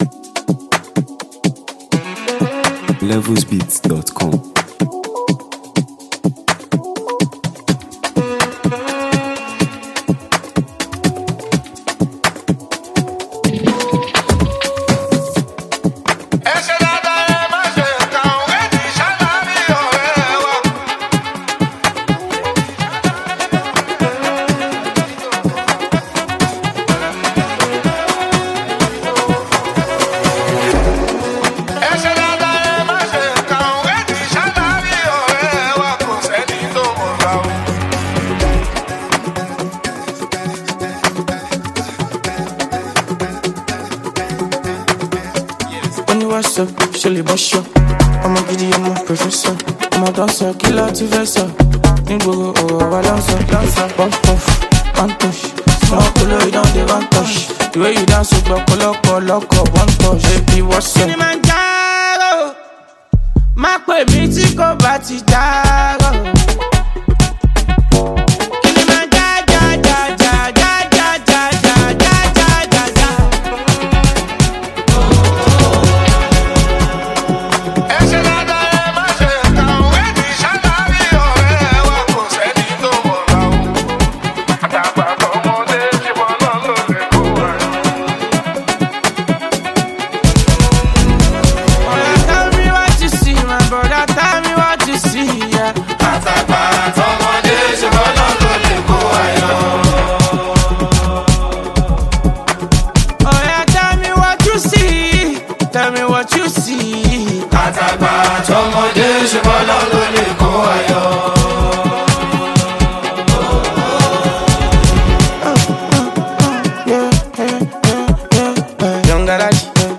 Highly talented Nigerian street-pop sensation and songwriter